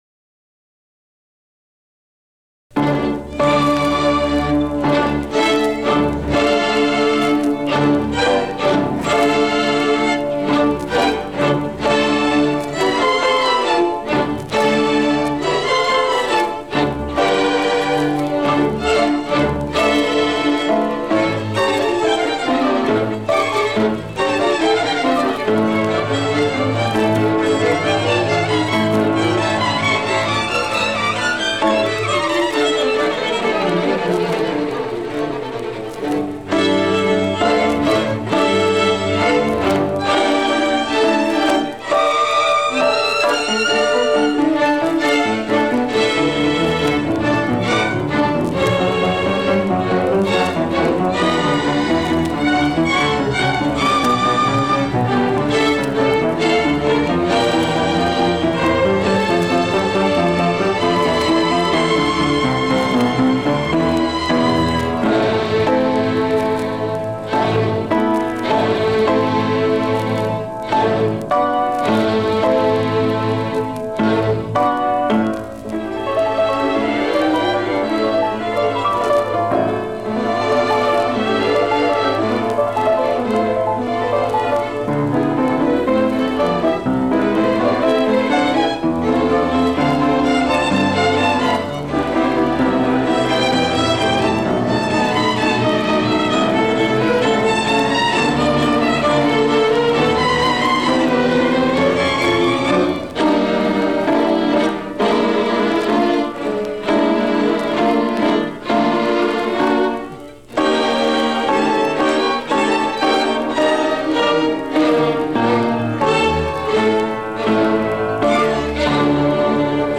4fc89556fdffac1b25687bc3dfc2910da9619ac8.mp3 Title 1955 Music in May band performance recording, part 2 Description An audio recording of the 1955 Music in May band performance, part 2 at Pacific University.
It brings outstanding high school music students together on the university campus for several days of lessons and events, culminating in the final concert that this recording preserves.